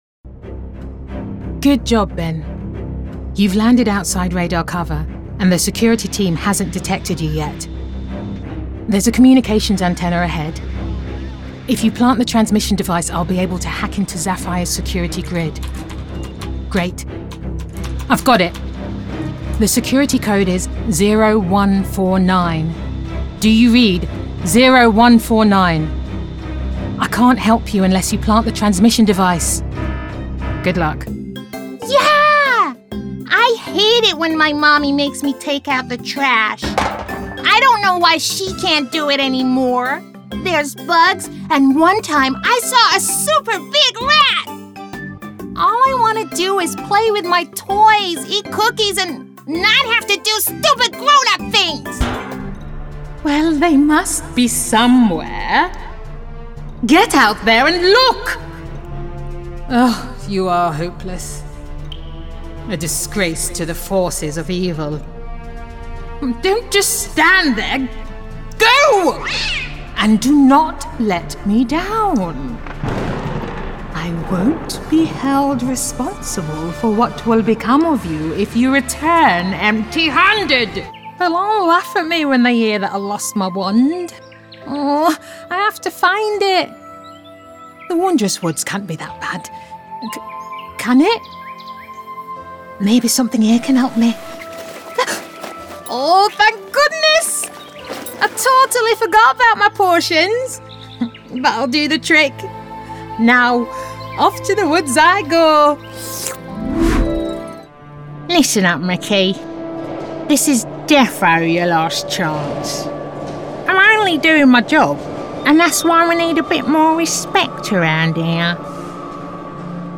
Energetic, Confident, Earthy, Street, Urban